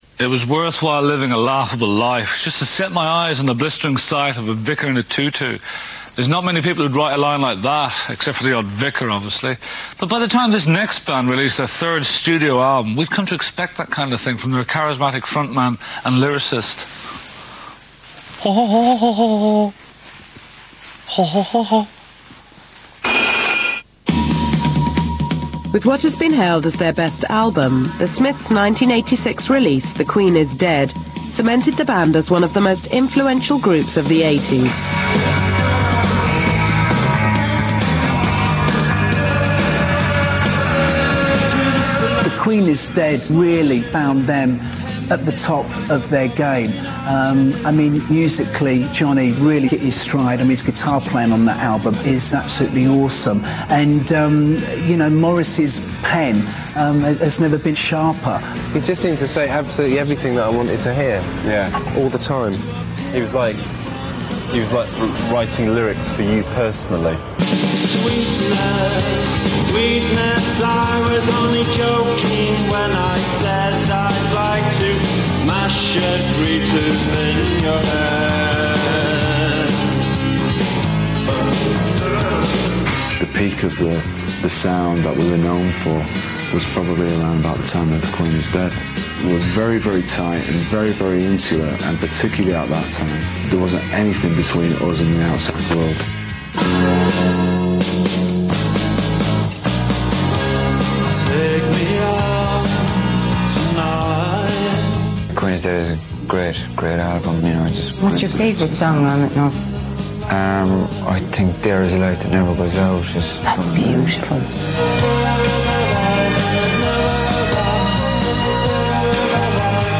I've attached a 3 min Real Audio file of the piece on The Queen Is Dead with comments from Johnny Marr, broadcaster Gary Crowley, Thom Yorke & Ed O'Brien of Radiohead, and Noel Hogan & Delores O'Riordan of The Cranberries.